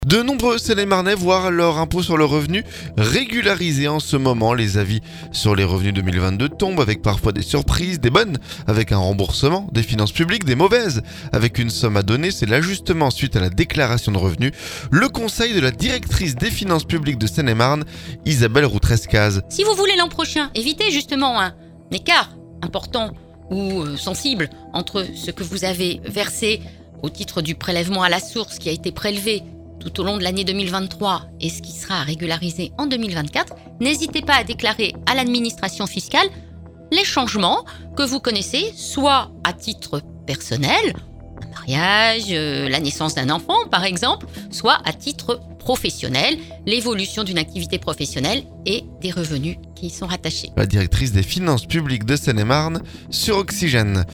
C'est l'ajustement suite à la déclaration de revenus. Le conseil de la directrice des Finances publiques de Seine-et-Marne, Isabelle Roux-Trescases.